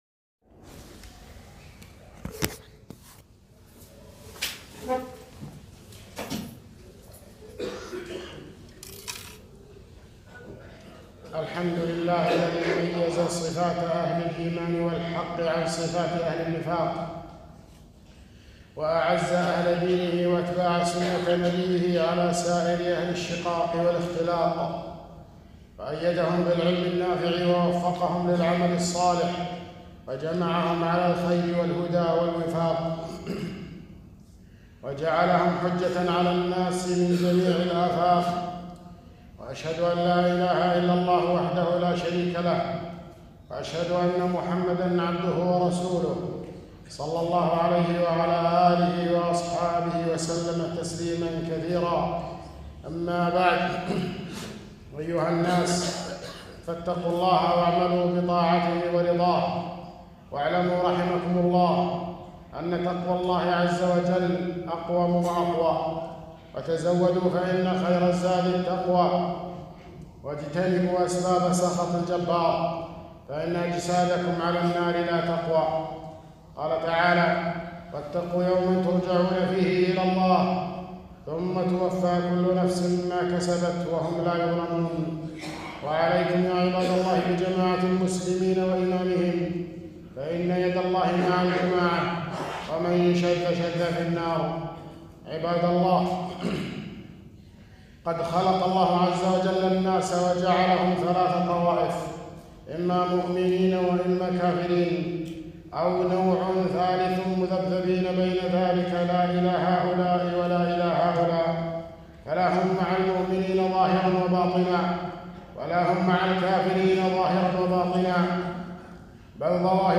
خطبة - صفات المنافقين